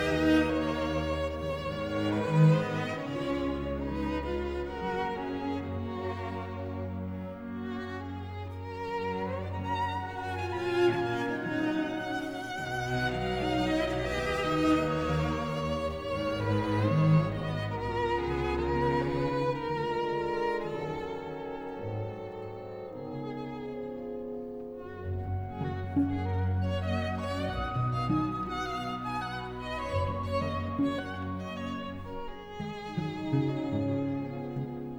Classical Crossover
Жанр: Классика